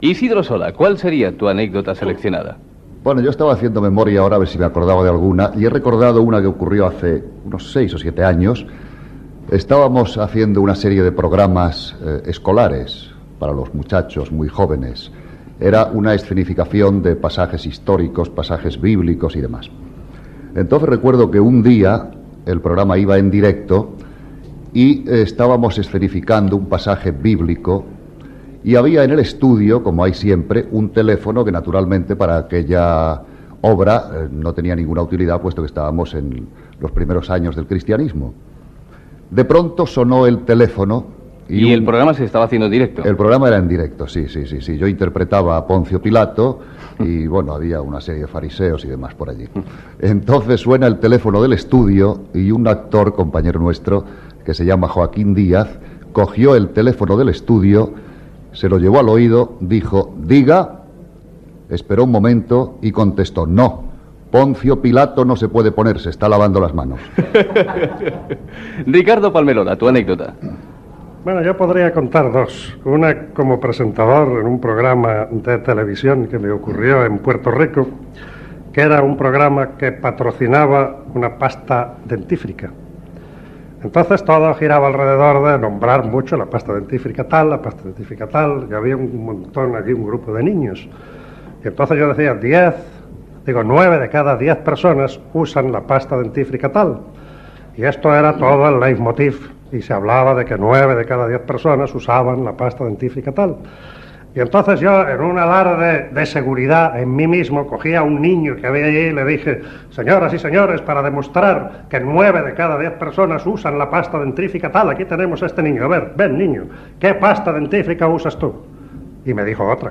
50 aniversari de Ràdio Barcelona. Programa fet des de l'estand de Sonimag a Barcelona.